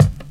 DnB Kit 1
kickldk03.wav